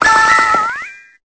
Cri de Ceriflor dans Pokémon Épée et Bouclier.